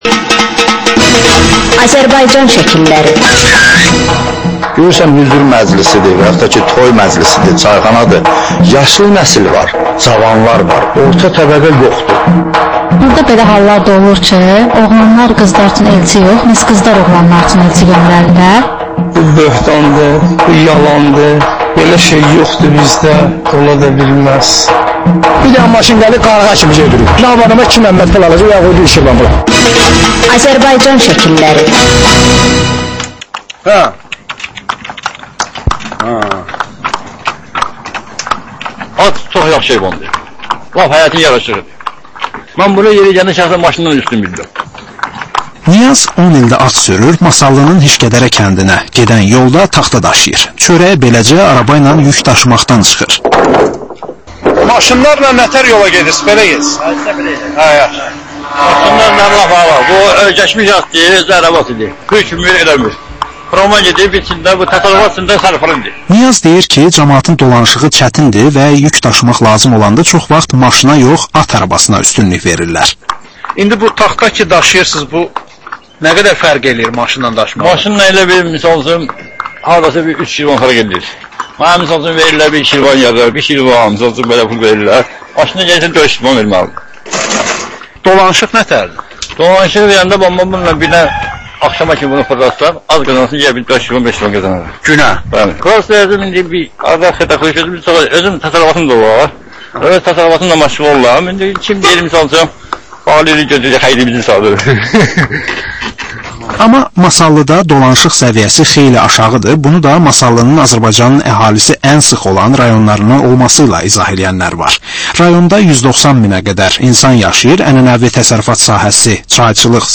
Azərbaycan Şəkilləri: Rayonlardan reportajlar.